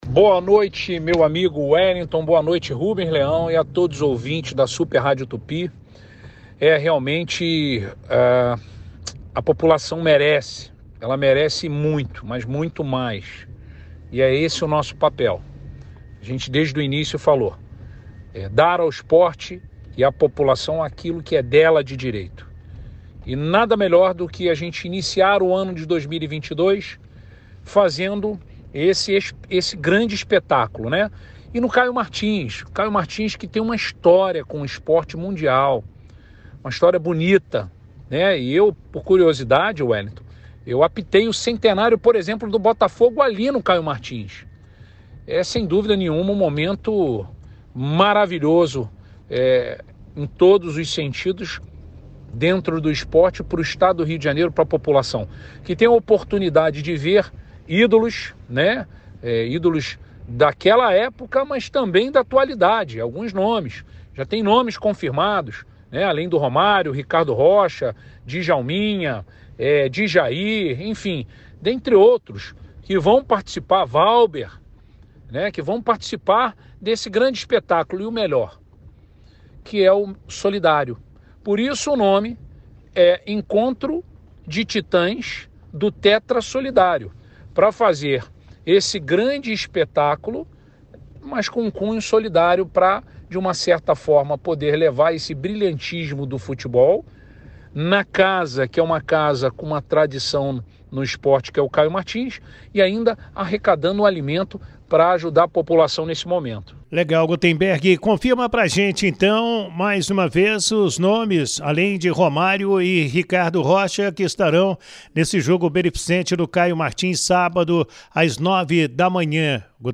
Em entrevista concedida à reportagem da Super Rádio Tupi, o secretário de Estado de Esporte e Lazer, Gutemberg Fonseca, falou sobre o evento cuja entrada custará 1kg de alimento não perecível. Ele ainda destacou que o Encontro de Titãs do Tetra Solidário marcará a abertura do calendário esportivo no Rio de Janeiro em 2022.